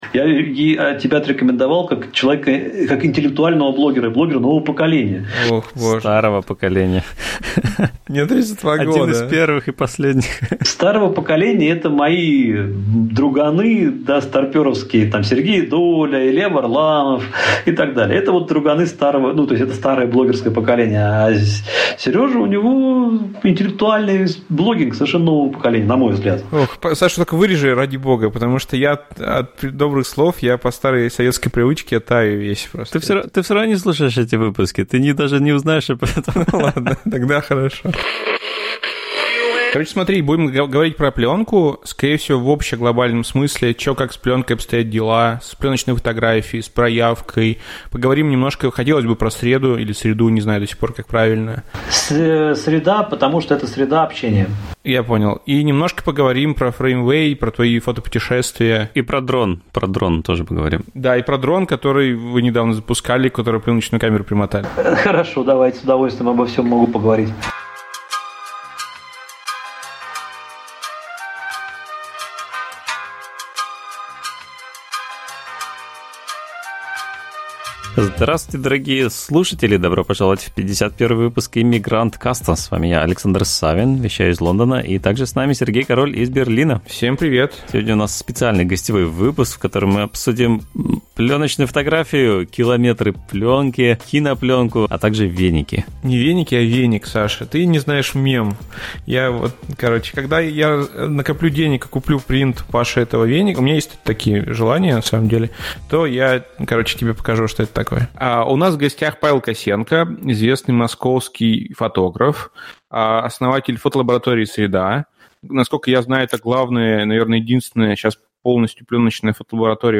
Подкаст о жизни в Лондоне, Берлине и Нью-Йорке, пленочной фотографии, инди-интернете, лодках, брекзите и дронах. На проводе гости от Сингапура и Гоа до Франции и США — от дизайнера шрифтов до пилота самолета.